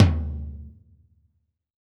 PTOM 3.wav